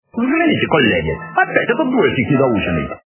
» Звуки » Люди фразы » Из к/ф - Взгляните коллеги, опять этот двоешник недоученный
При прослушивании Из к/ф - Взгляните коллеги, опять этот двоешник недоученный качество понижено и присутствуют гудки.